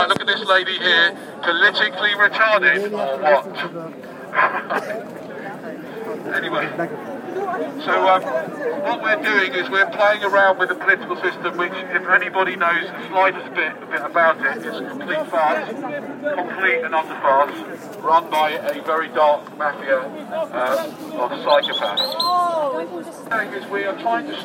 fire alarm gathering plus protester
Yesterday I was at the School of Oriental and African Studies at UCL, and a fire alarm went so everyone had to go outside. This was confused by this guy with a loud hailer doing a weird 'performance art' / protest.